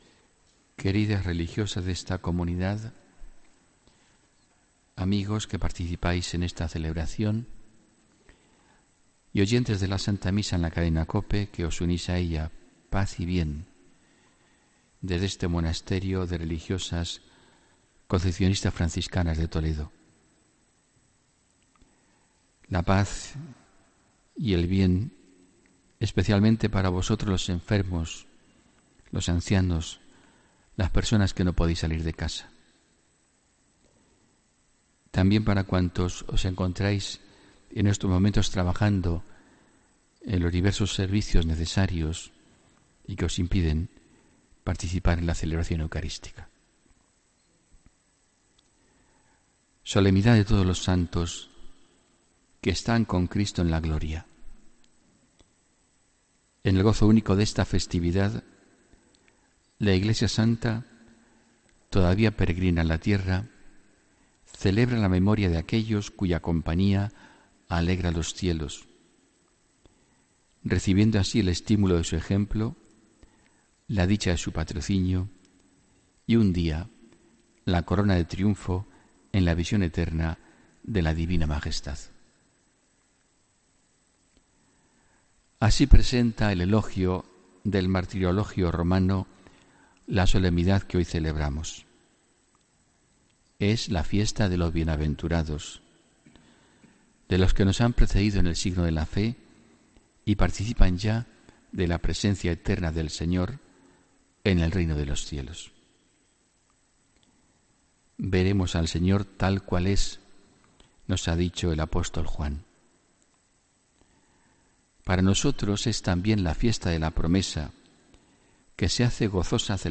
Escucha la Homilía del 1 de noviembre de 2017